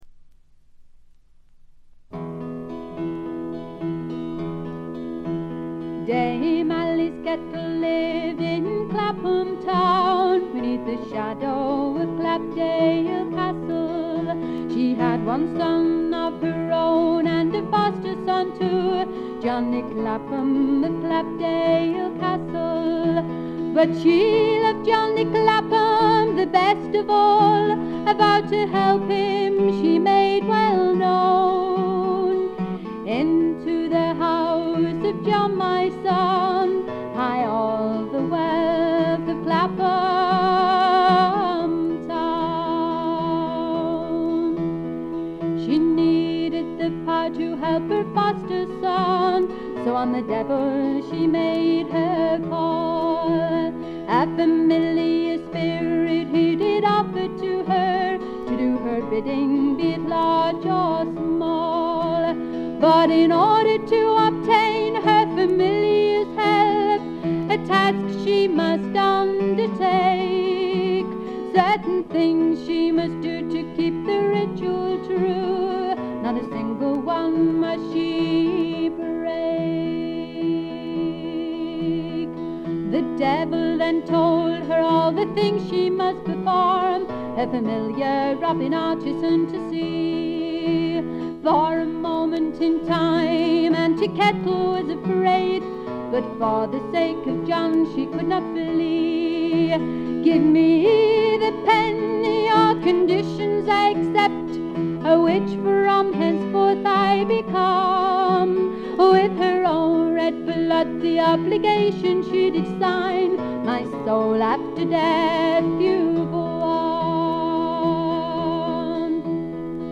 部分試聴ですが、ほとんどノイズ感無し。
若々しさ溢れるヴォーカルがとてもよいですね。
ごくシンプルな伴奏ながら、躍動感が感じられる快作！
コンサーティナの哀愁と郷愁をかきたてる音色がたまりません。
試聴曲は現品からの取り込み音源です。